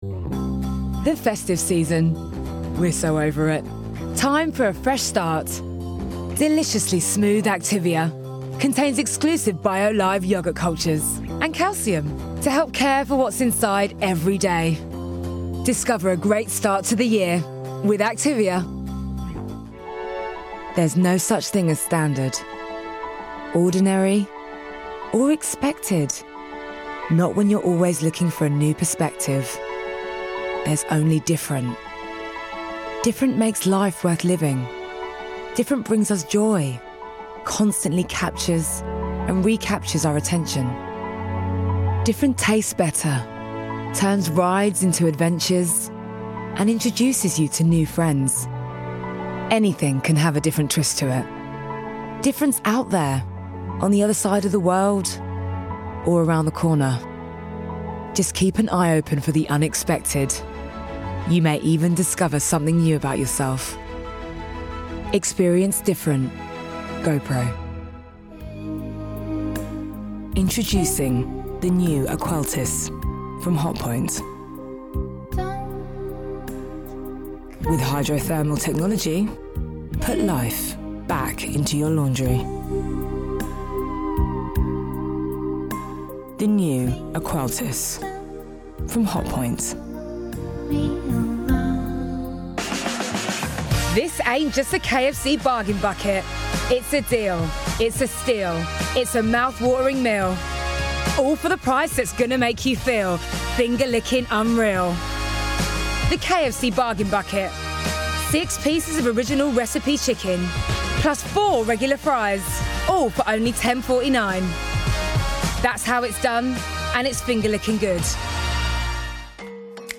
Neutral London.
• Female
• London
polished, smooth